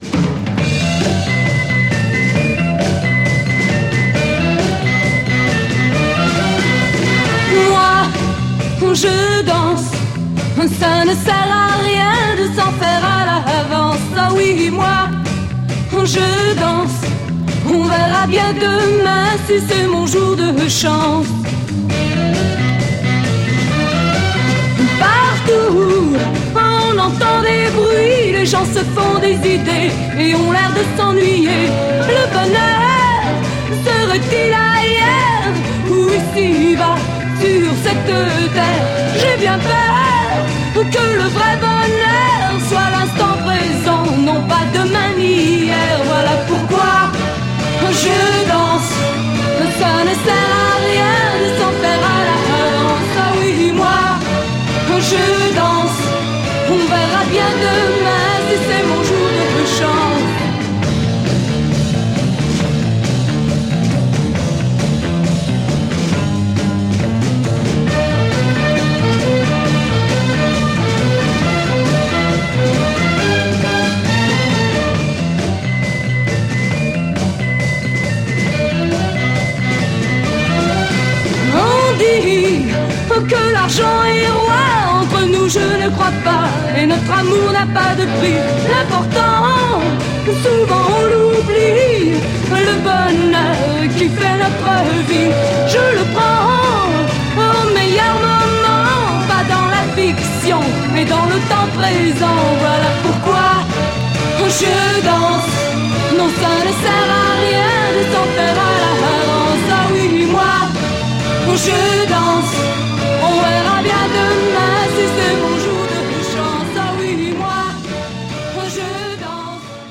Female French Freakbeat Mod Dancer EP
cover version in French